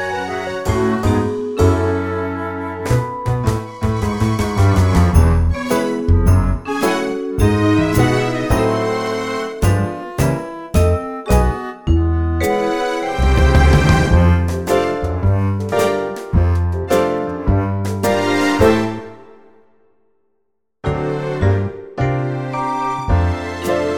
no Vocals At All Soundtracks 1:59 Buy £1.50